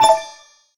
ui_menu_button_confirm_01.wav